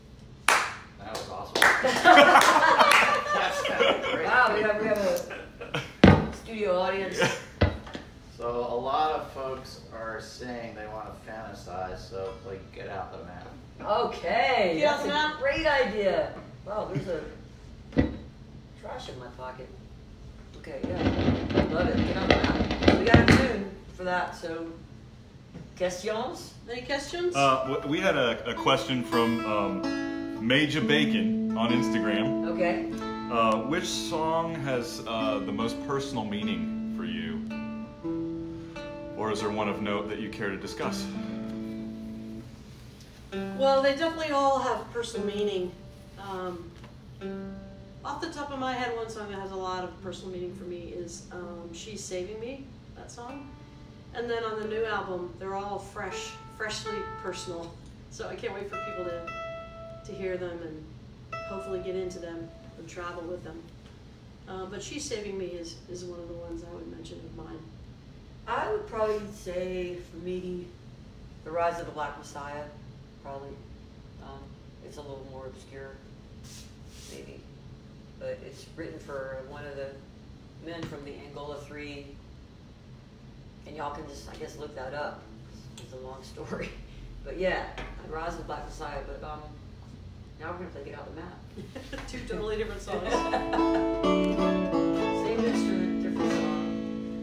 lifeblood: bootlegs: 2020-03-19: facebook live online show
16. talking with the crowd (1:37)